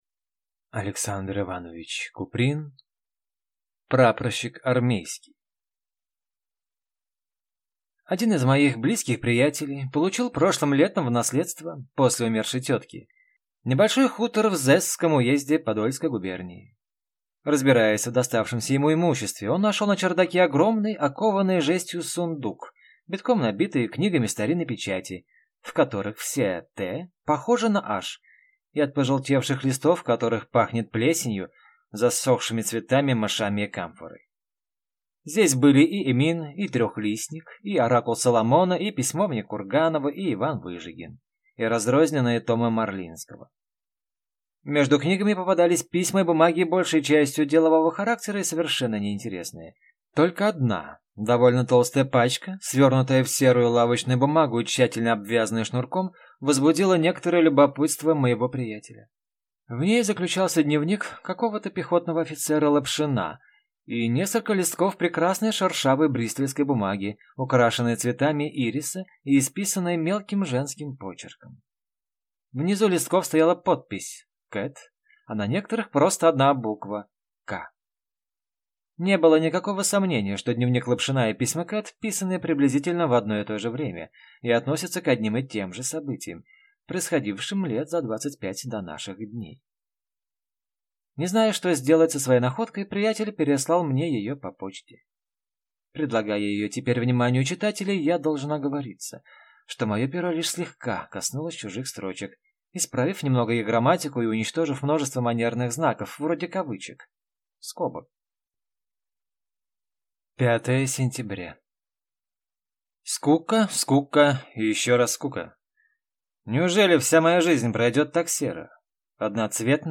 Аудиокнига Прапорщик армейский | Библиотека аудиокниг